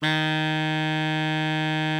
bari_sax_051.wav